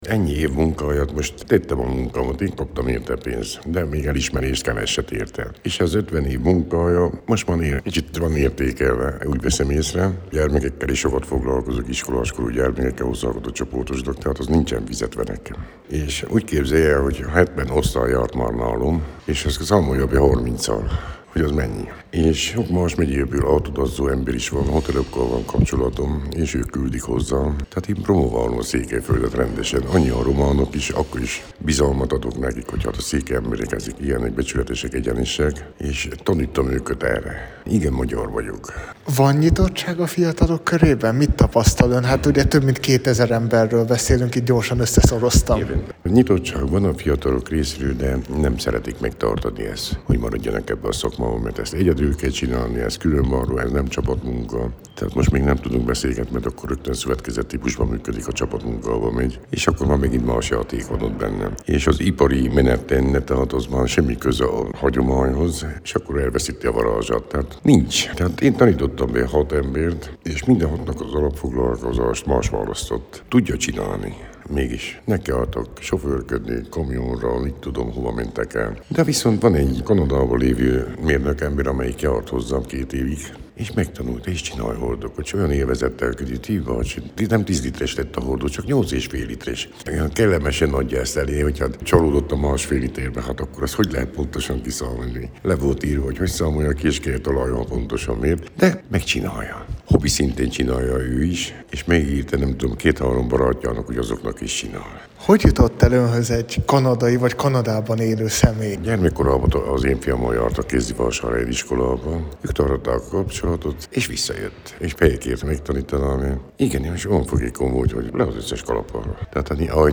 Az ünnepélyes díjátadóra október 1-én került sor Marosvásárhelyen, a Kultúrpalotában.